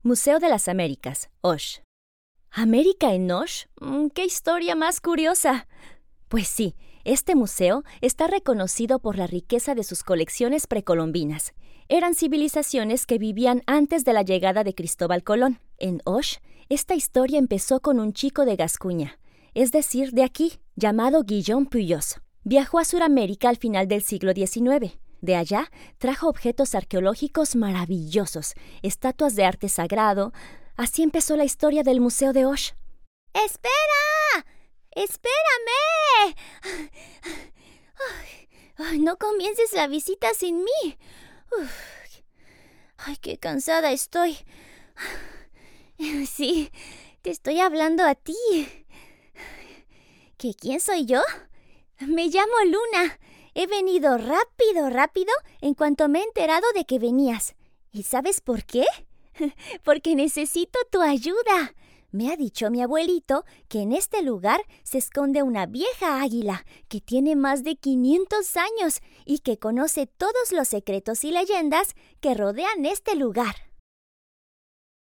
Spanisch (Lateinamerikanisch)
Junge, Natürlich, Freundlich, Sanft, Corporate
Audioguide
Her accent is known to be “neutral” or “international”.